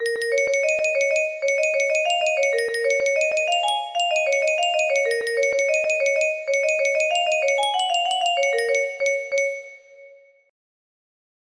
random riff music box melody
Grand Illusions 30 music boxes More